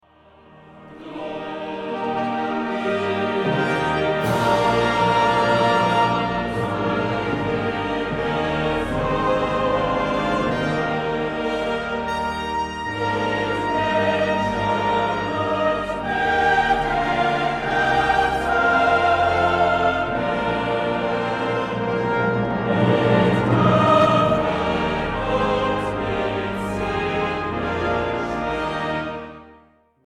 choral music